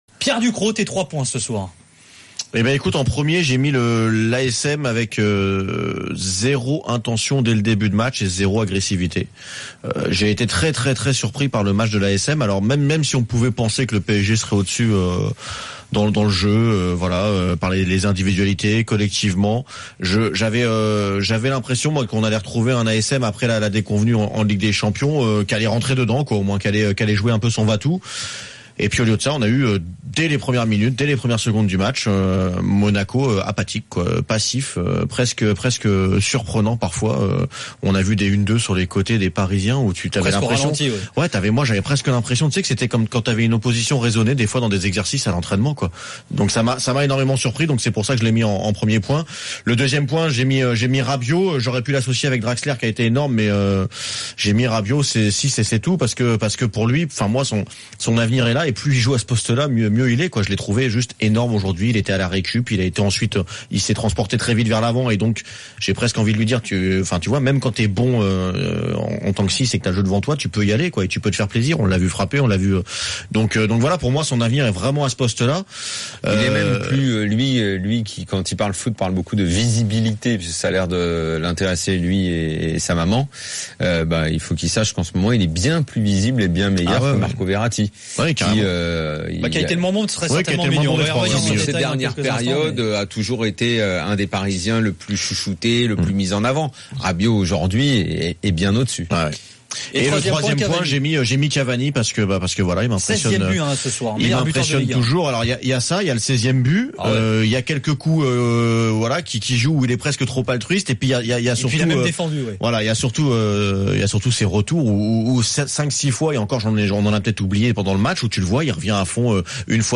Chaque jour, écoutez le Best-of de l'Afterfoot, sur RMC la radio du Sport.